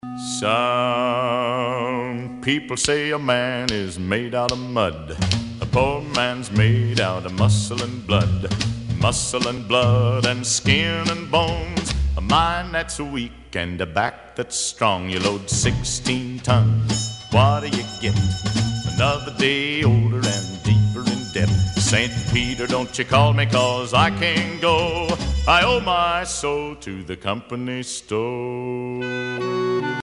Oldies